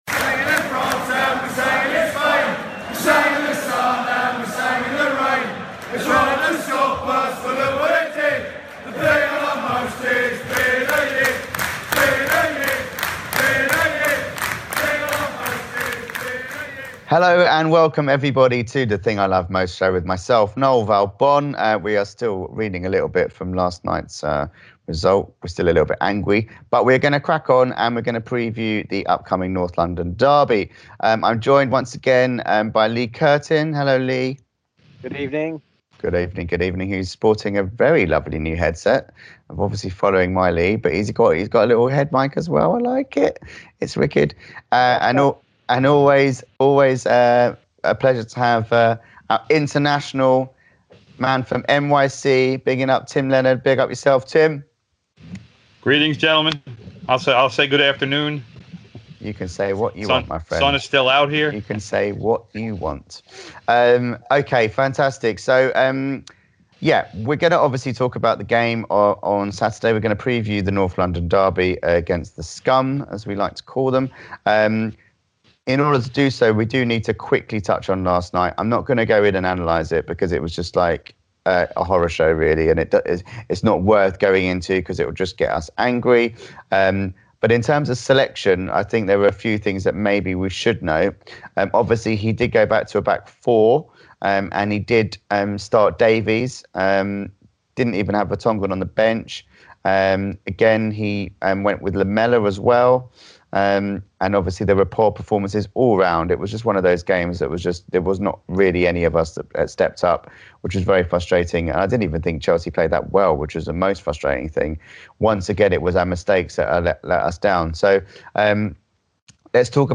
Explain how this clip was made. In this week's Skype show